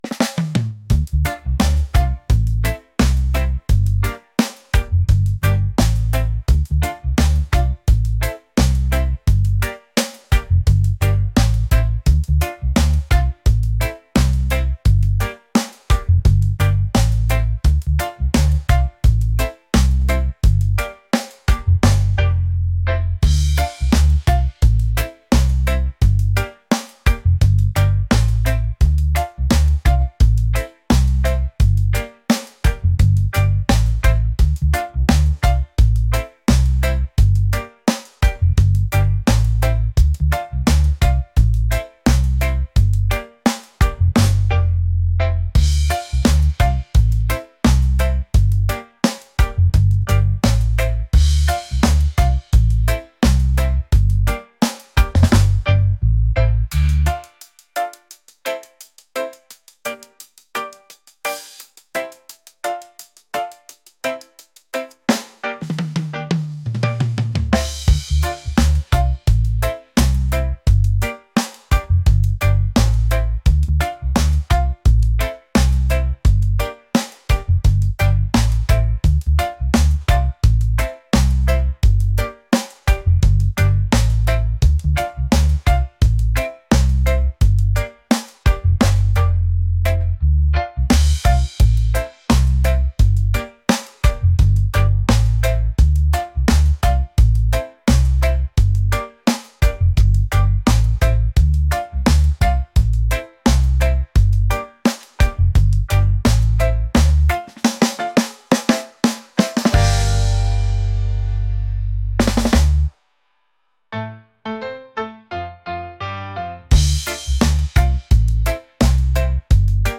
reggae | groovy | laid-back